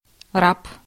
Ääntäminen
IPA: [va.lɛ]